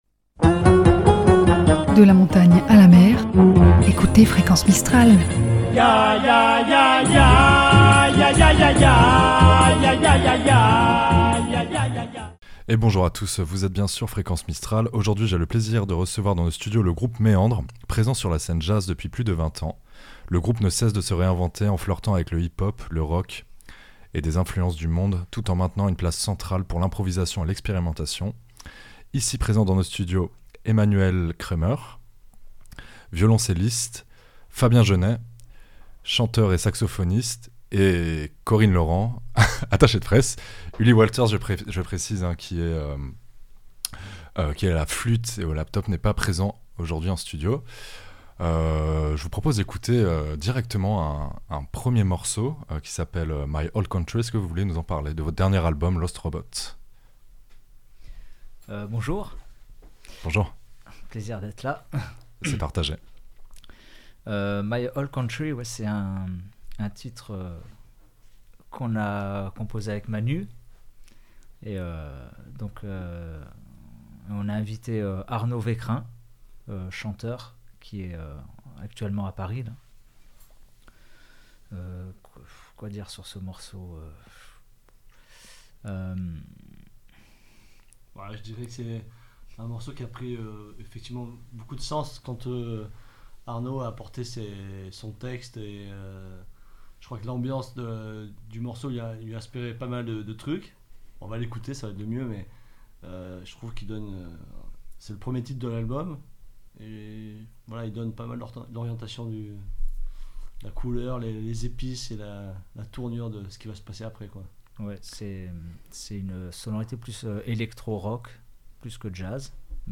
"Les chants de Mars" épisode 6: Méandres, l'éclectisme sonore Lundi 24 Février 2025 Nous avons eu le plaisir de recevoir dans nos studios le groupe Méandres présent sur la scène jazz depuis plus de 20 ans. Le groupe ne cesse de se réinventer en flirtant avec le hip-hop, le rock et les influences du monde tout en maintenant une place centrale pour l'improvisation et l’expérimentation.